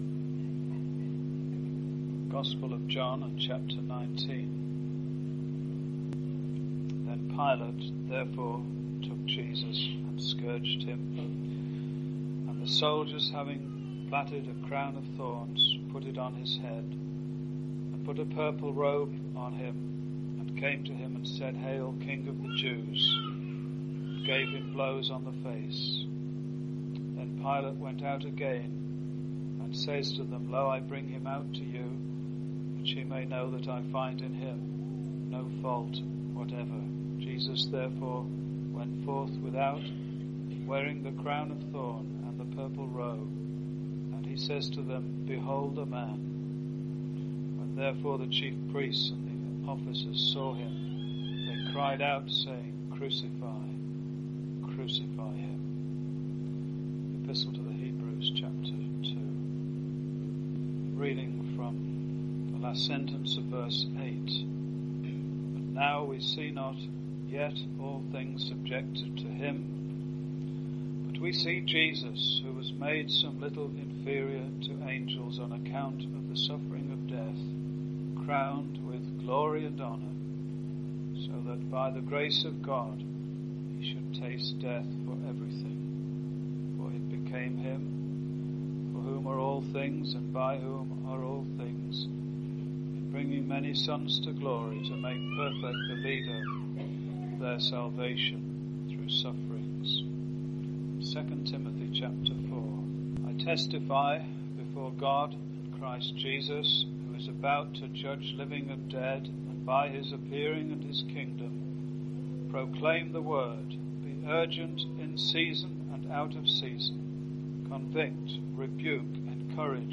There is coming a day when Jesus will publically appear as the King of Kings and the Lord of Lords crowned with glory and honour. In this address, you will hear about a man-made Crown and a God made Crown.